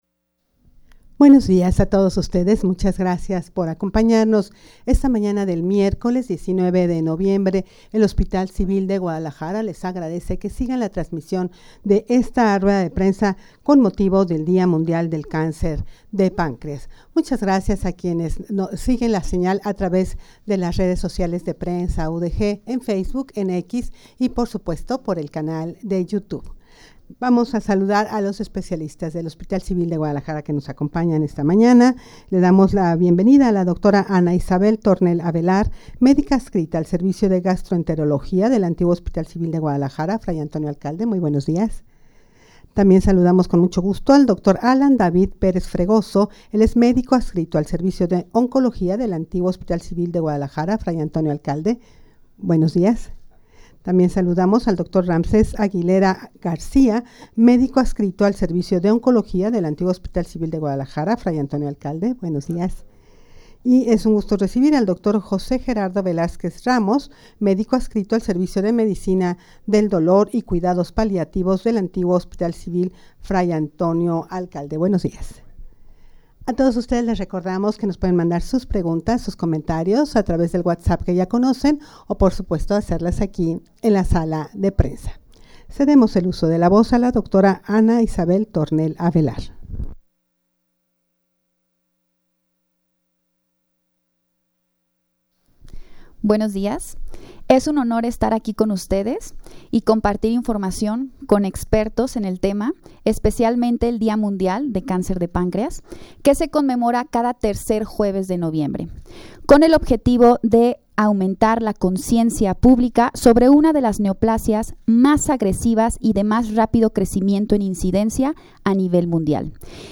rueda-de-prensa-con-motivo-del-dia-mundial-del-cancer-de-pancreas.mp3